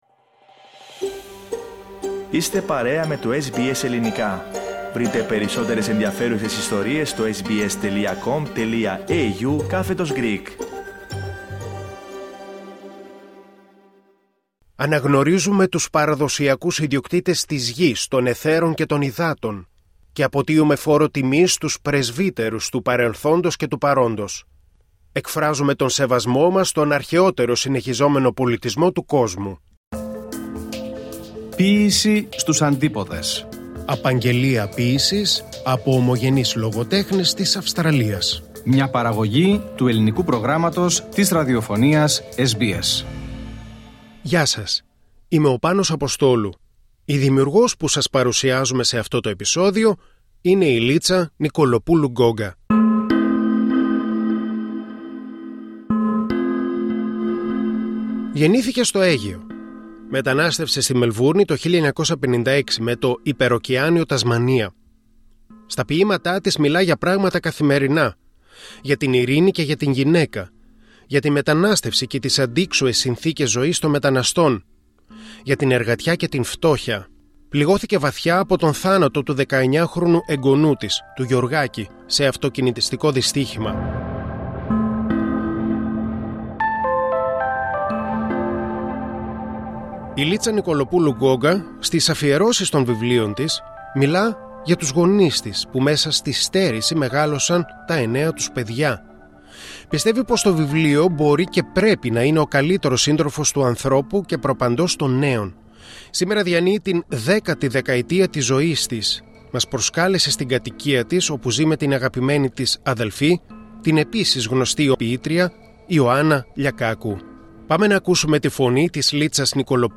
Δε θα καταφέρει να το ολοκληρώσει και συνδράμαμε ώστε να ακουστεί ολόκληρο.